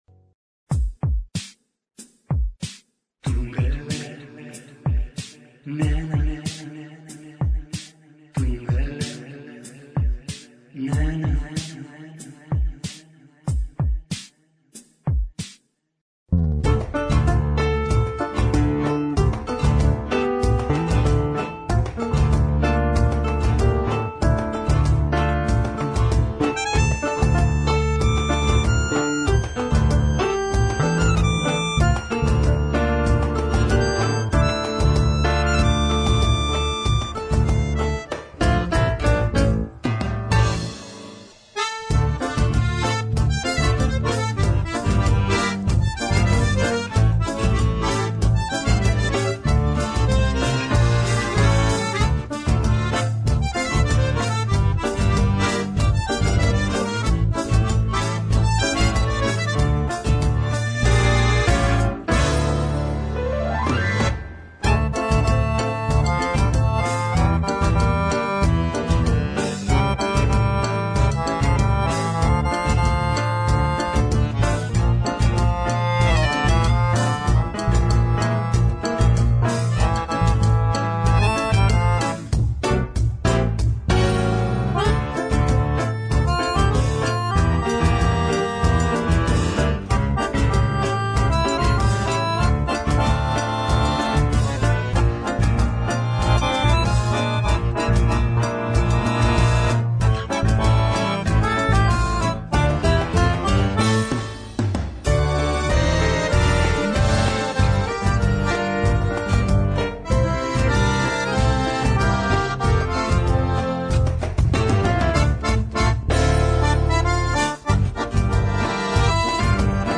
Entrevista con el autor